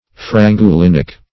Search Result for " frangulinic" : The Collaborative International Dictionary of English v.0.48: Frangulic \Fran*gu"lic\, Frangulinic \Fran`gu*lin"ic\, a. (Chem.)